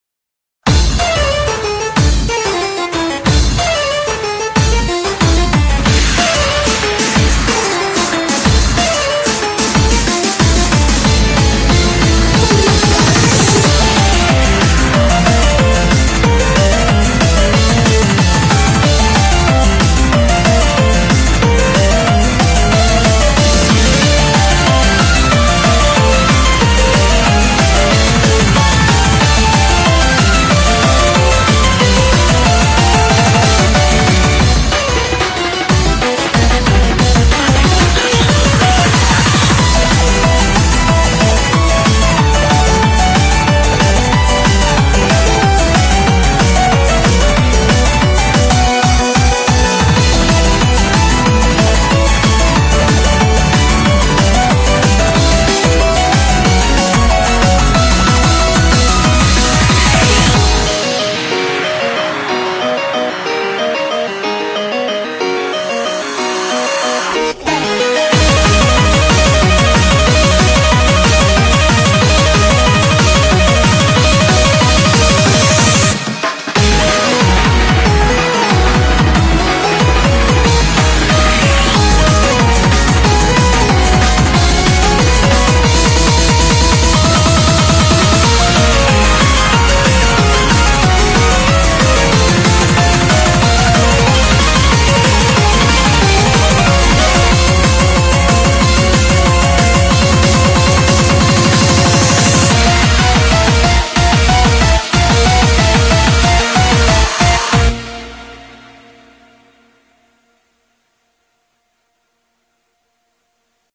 BPM92-185
Audio QualityPerfect (Low Quality)